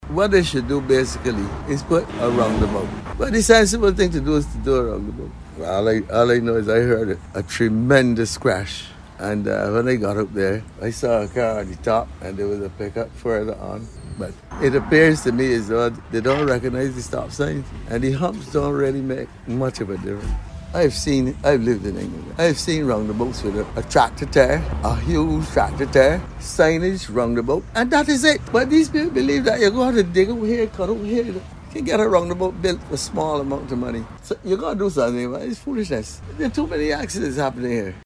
Voice of: Residents in Mangrove, St. Philip.